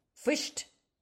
Each week, SAY IT IN IRISH features an Irish or Hiberno-English word or phrase, exploring its meaning, history and origins – with an audio recording by a native Irish speaker from Cork so you can hear how it’s pronounced.
Fuist – pronounced roughly fwi-sht